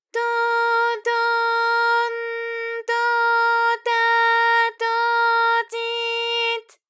ALYS-DB-001-JPN - First Japanese UTAU vocal library of ALYS.
to_to_n_to_ta_to_ti_t.wav